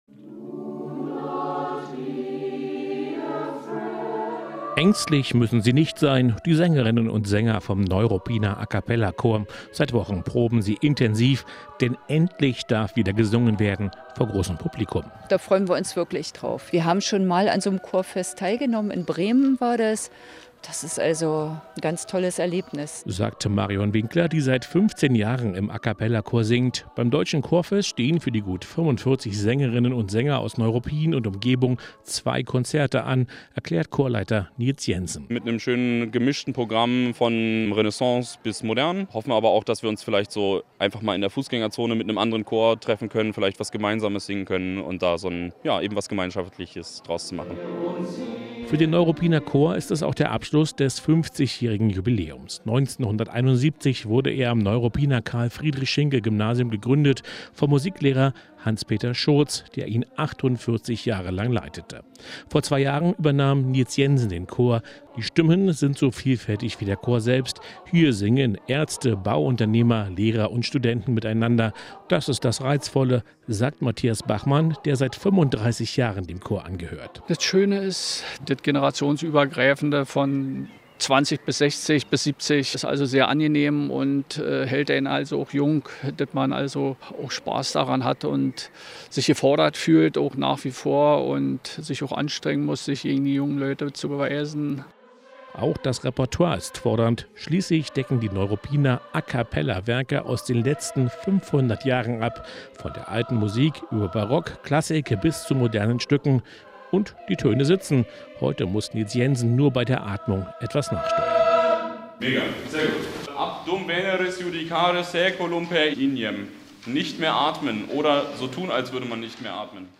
350 Chöre, vier Tage und 500 Konzerte - Leipzig wird mit dem Deutschen Chorfest zum Mekka der Deutschen Chorszene. Mit dabei ist der Neuruppiner A-Cappella-Chor, einer der traditionsreichsten Chöre aus Brandenburg.